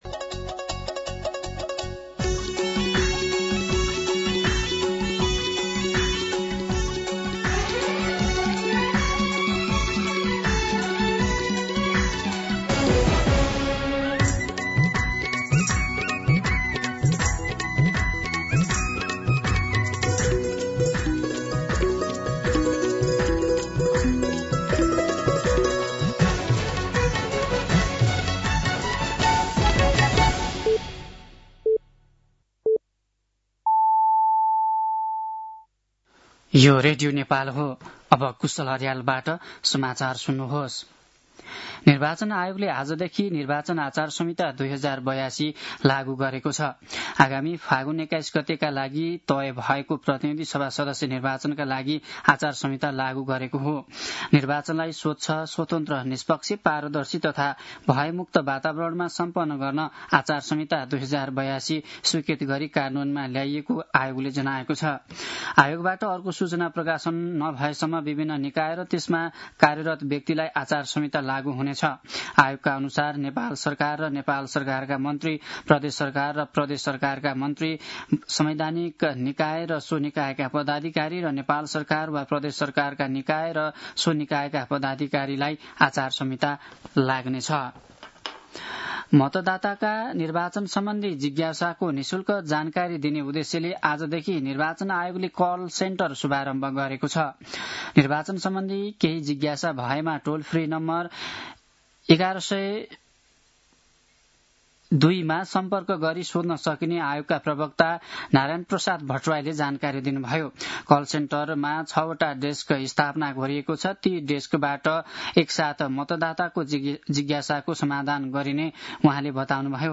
दिउँसो ४ बजेको नेपाली समाचार : ५ माघ , २०८२
4-pm-News-.mp3